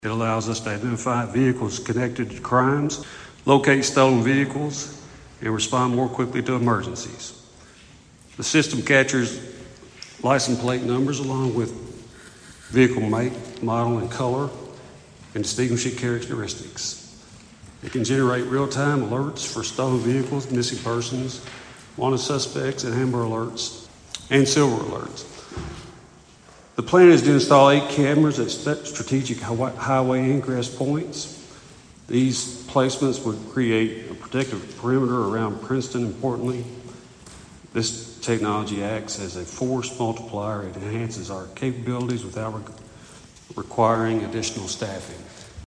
At Monday night’s meeting, the Princeton City Council approved applying for a grant to install automated license plate reader cameras at key city entry points.